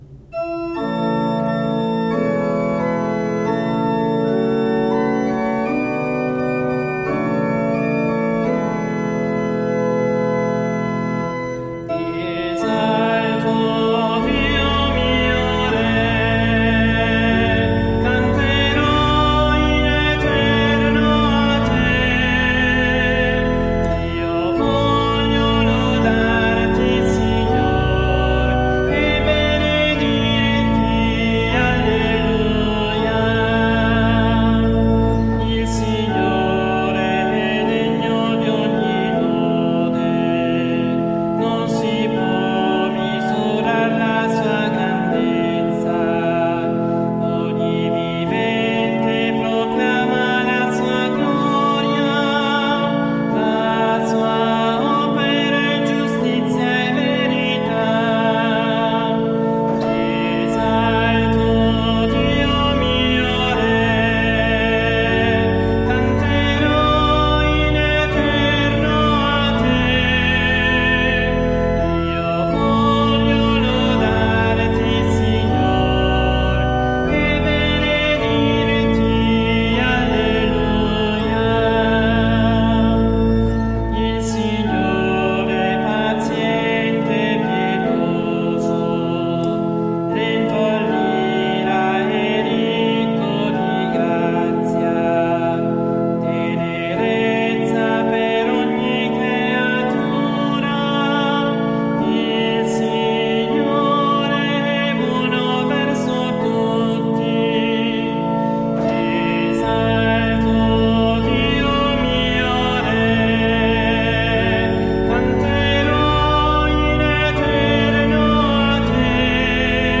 All'organo Agati
organista e solista